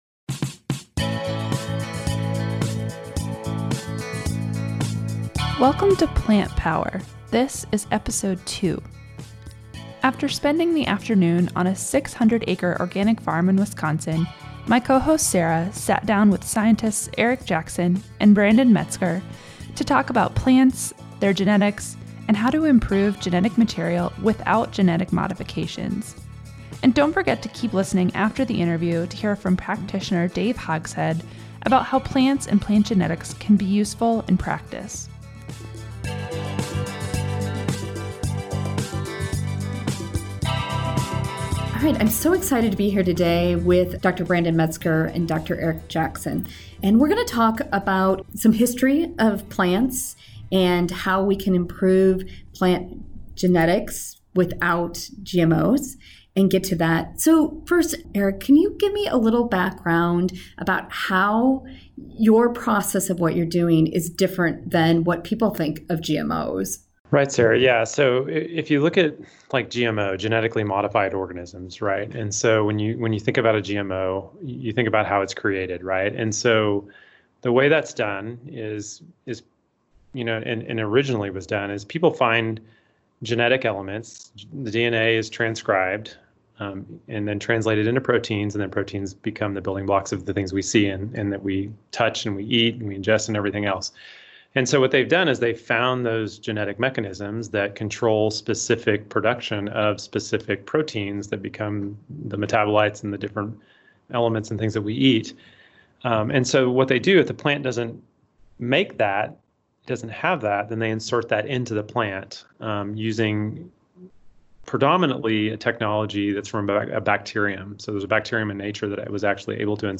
Plant Power Episode 2: our guests give a brief history of plant genetics, discussing how to enhance nutrient density with natural selection.